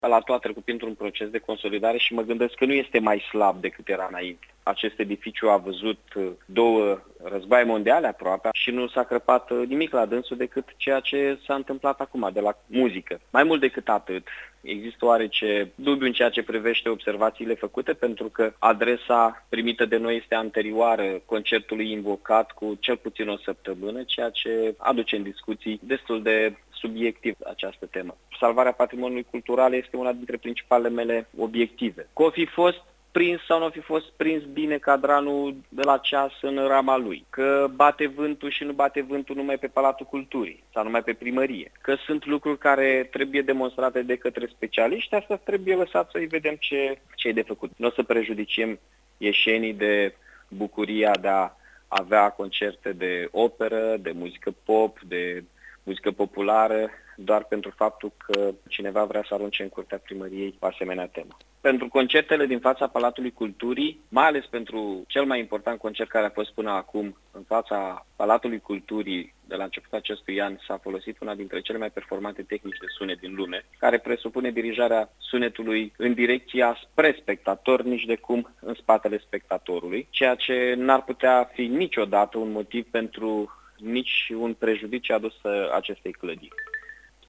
Primarul Iașului, Mihai Chirica, a răspuns, astăzi, sesizărilor formulate de Consiliul Județean prin care se atrăgea atenția că la ultimele concerte care au avut loc în Piaţa Palatului nivelul decibelilor a depăşit limita maximă admisă prin lege şi ca urmare a acestui lucru a fost afectat mecanismul ceasului din turnul Palatului şi au apărut crăpături în pereţii frontali: